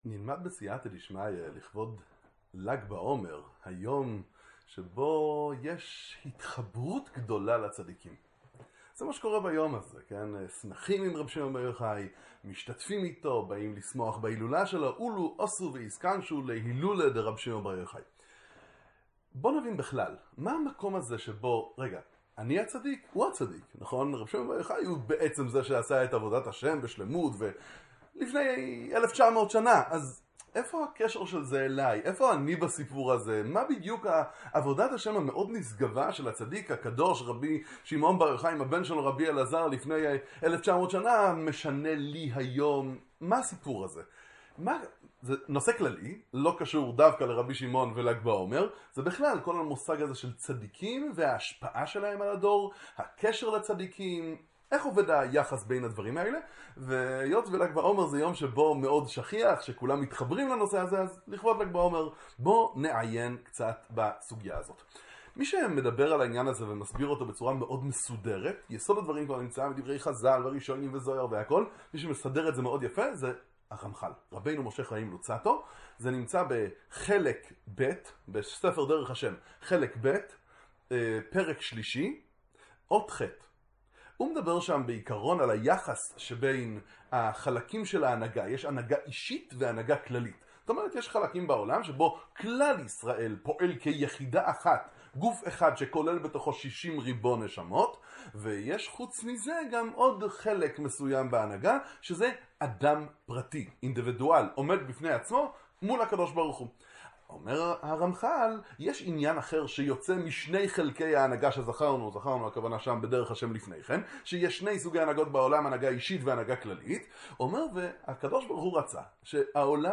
שיעורי תורה